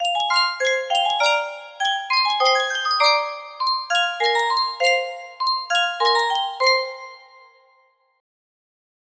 Adapted for 18 note comb